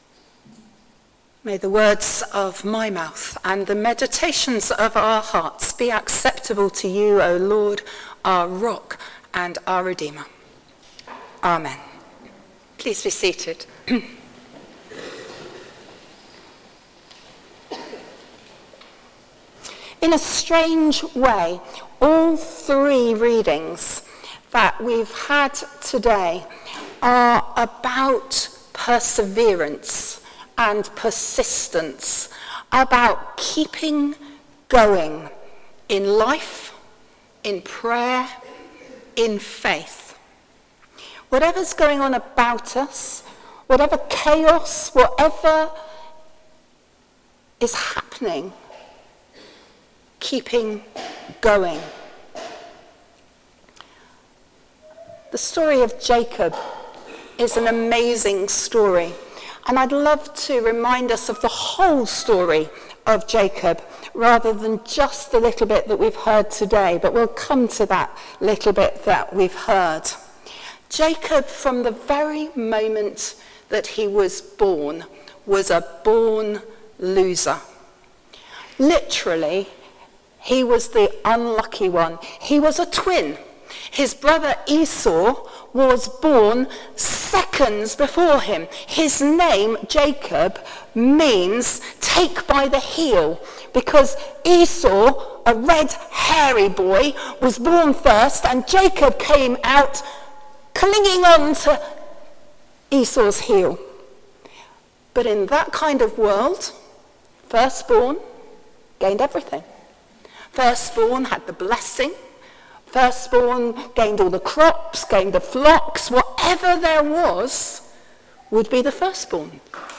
Sermon: Keep going | St Paul + St Stephen Gloucester